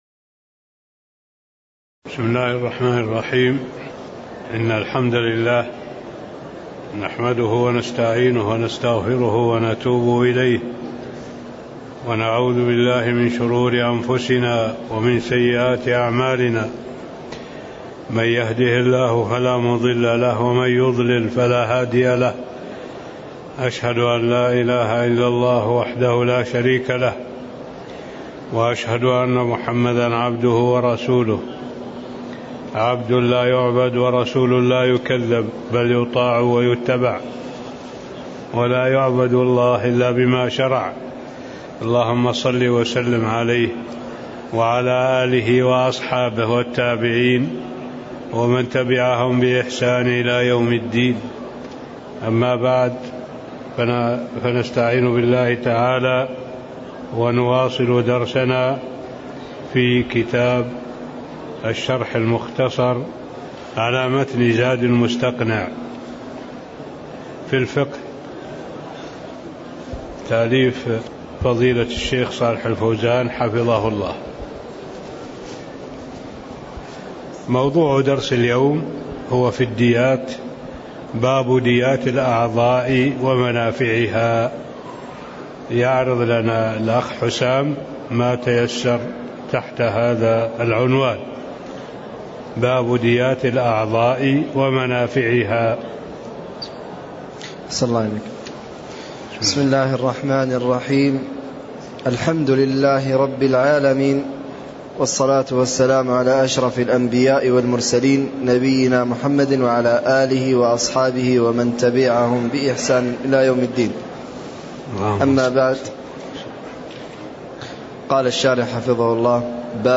تاريخ النشر ٢٠ شوال ١٤٣٥ هـ المكان: المسجد النبوي الشيخ: معالي الشيخ الدكتور صالح بن عبد الله العبود معالي الشيخ الدكتور صالح بن عبد الله العبود باب ديات الأعضاء ومنافعها (05)